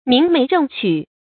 明媒正娶 注音： ㄇㄧㄥˊ ㄇㄟˊ ㄓㄥˋ ㄑㄩˇ 讀音讀法： 意思解釋： 明、正：形容正大光明。舊指正式婚姻。